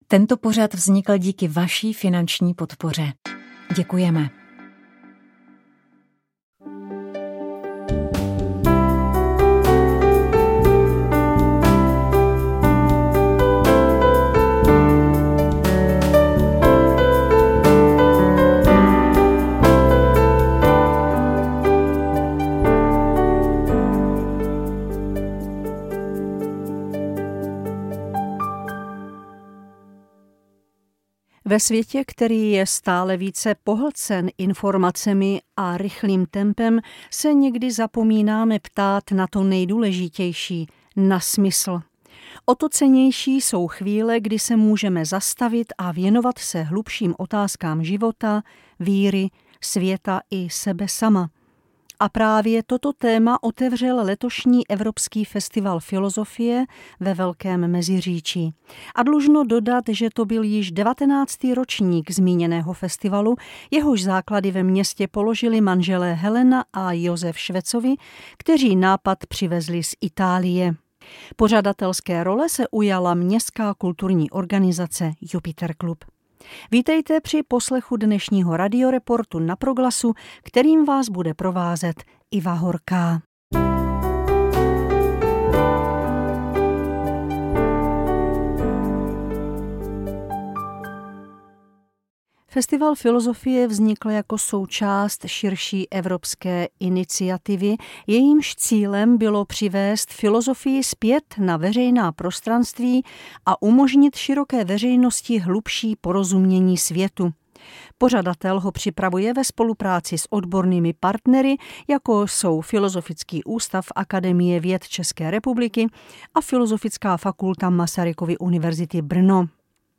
V pořadu uslyšíme mimo jiné shrnutí historie EFF, ale především také autentické ukázky z přednášek z letošního ročníku na téma Filozofie a náboženství.